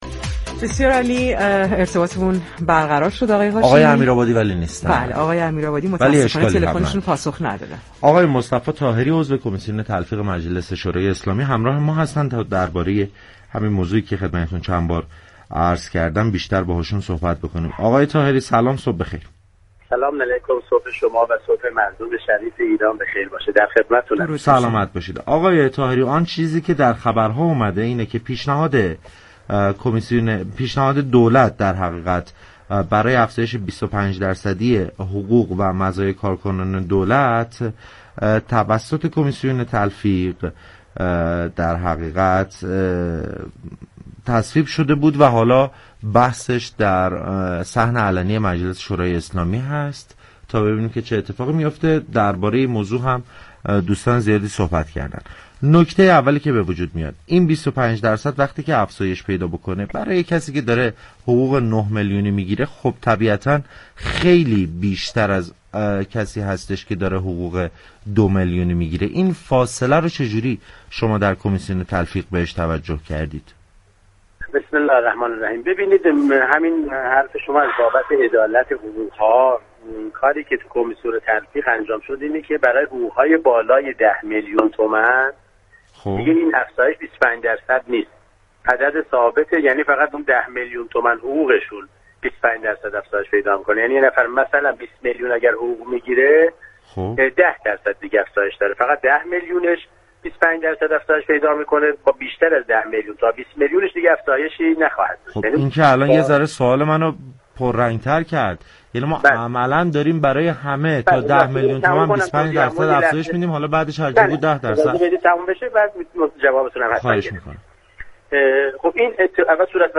در همین راستا برای آگاهی از جزئیات بیشتر این مصوبه، برنامه پارك شهر 16 اسفند با مصطفی طاهری عضو كمیسیون تلفیق مجلس شورای اسلامی گفتگو كرد.